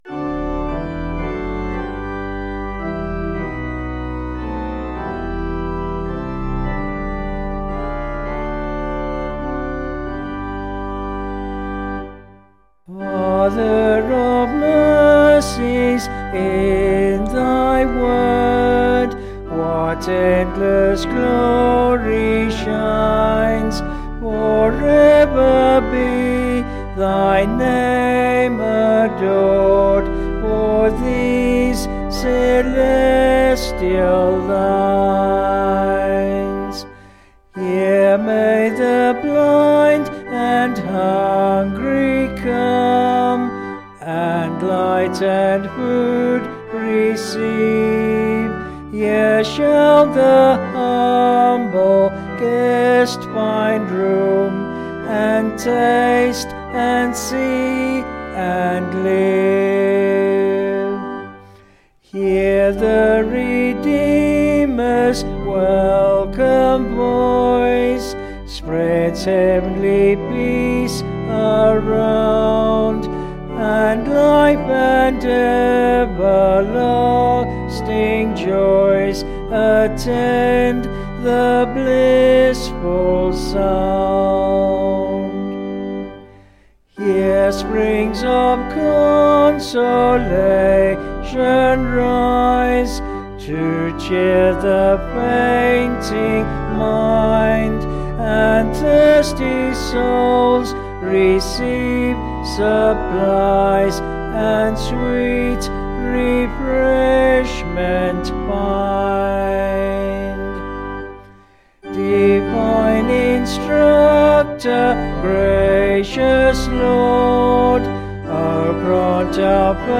Vocals and Organ   264.3kb Sung Lyrics